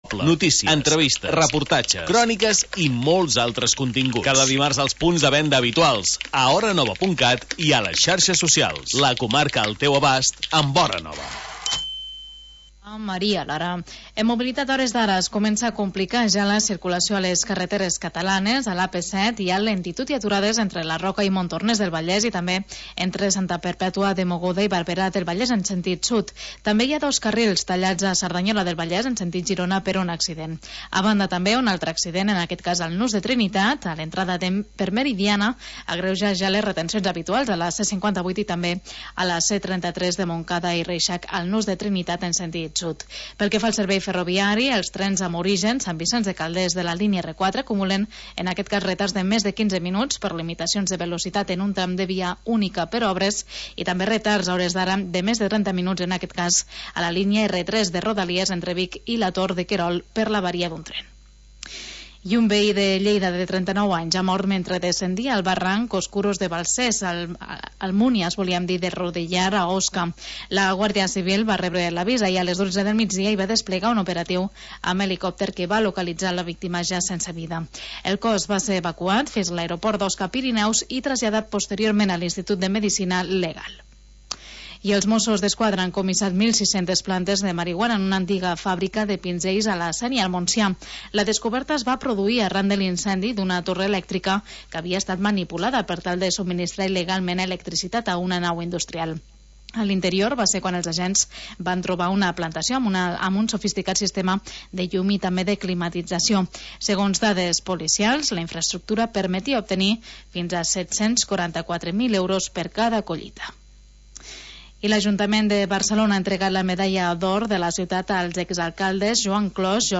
Magazín territorial d'estiu